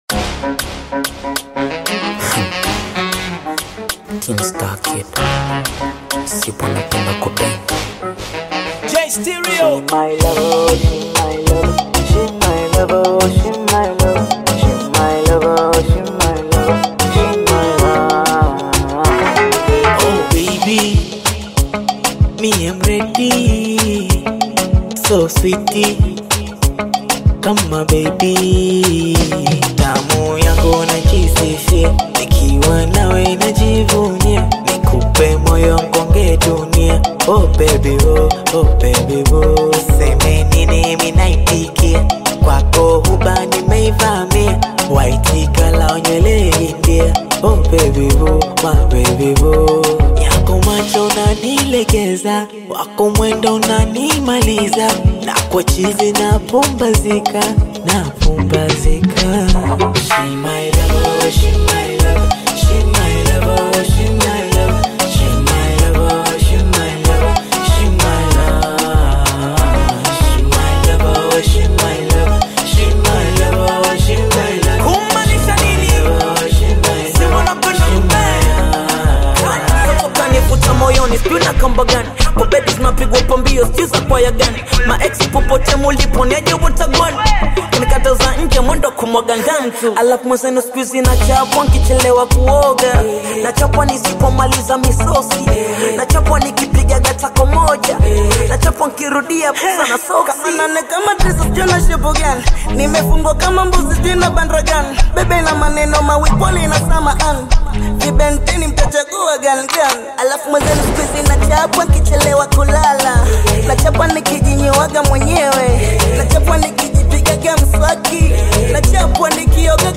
bongo flava
African Music